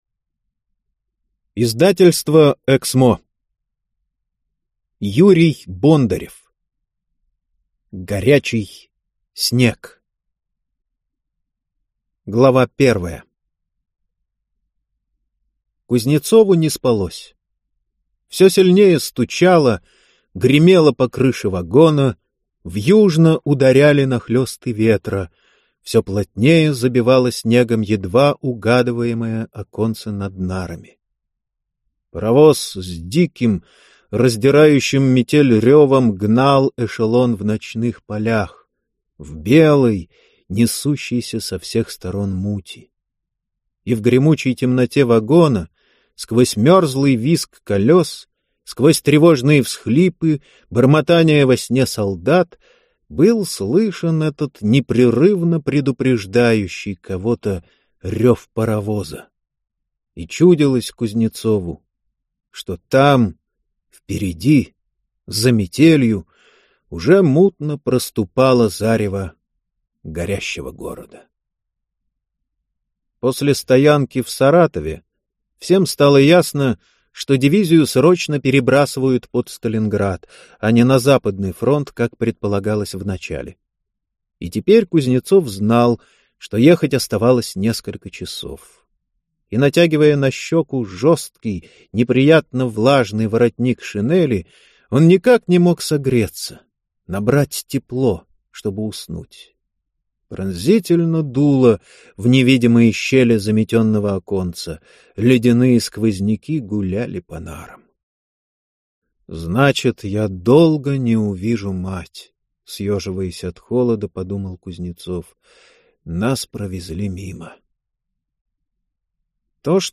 Аудиокнига Горячий снег | Библиотека аудиокниг